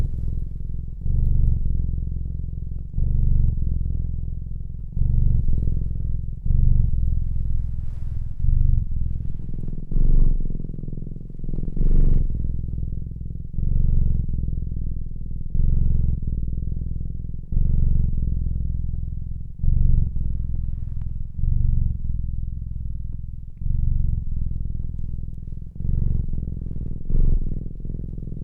Cat Purring Sound Effect Free Download
Cat Purring